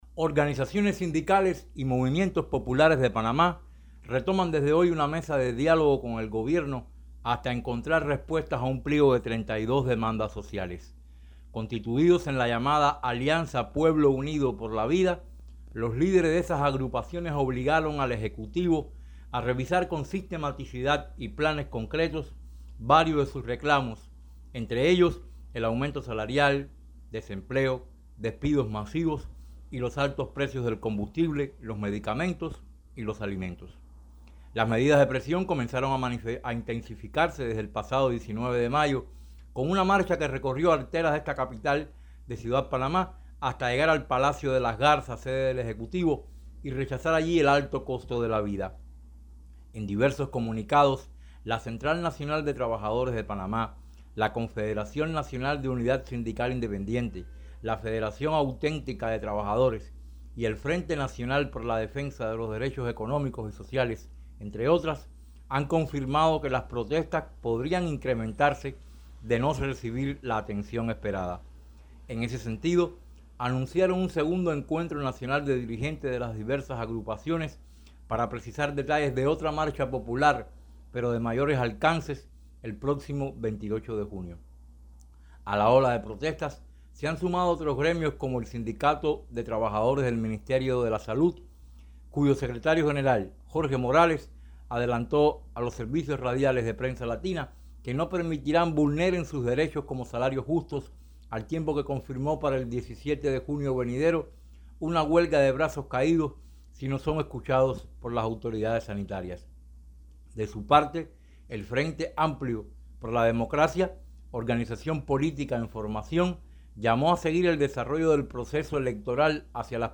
desde Ciudad de Panamá